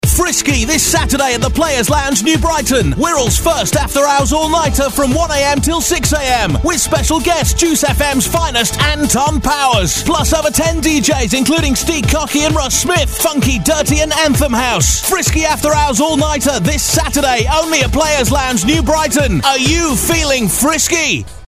Play 'Frisky' Radio Advert THE PLAYAS LOUNGE - New Brighton